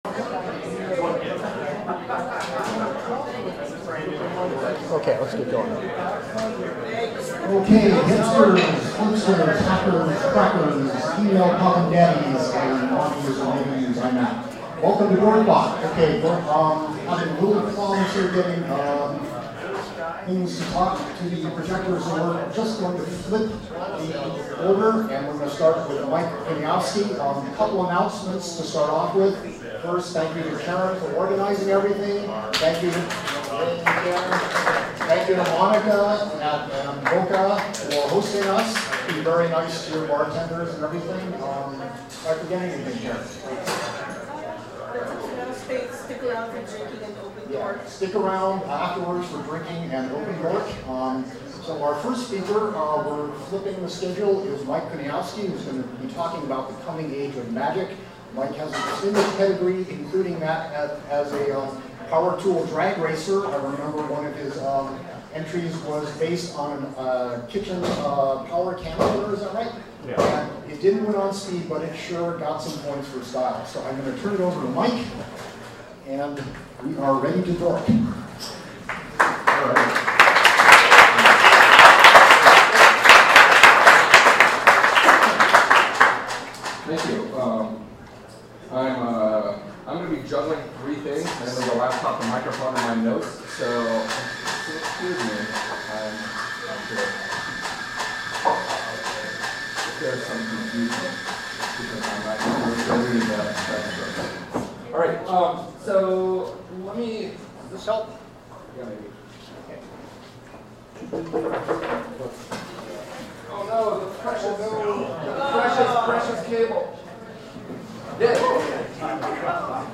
This was recorded with a second generation iPod Nano, a MicroMemo, & a generic RadioShack microphone. A direct feed to the PA system would have avoided the echo – we’ll do that next time.